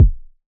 edm-kick-35.wav